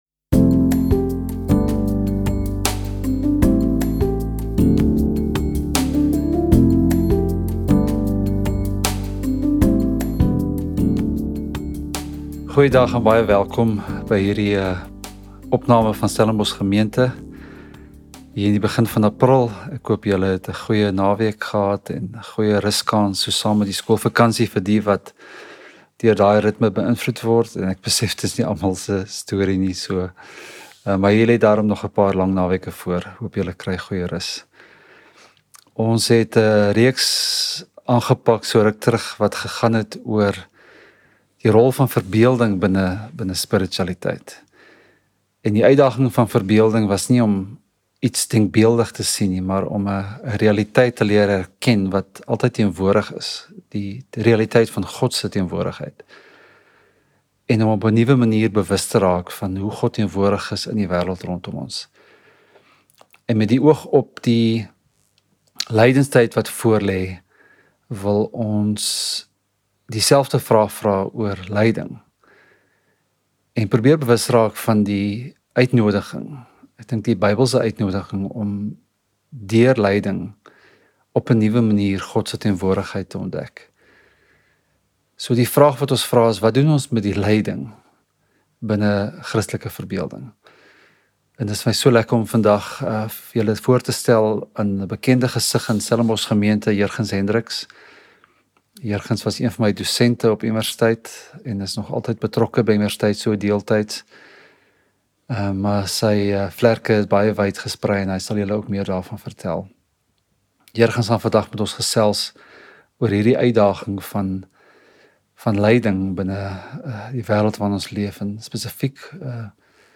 Preek | Sondag, 16 Februarie.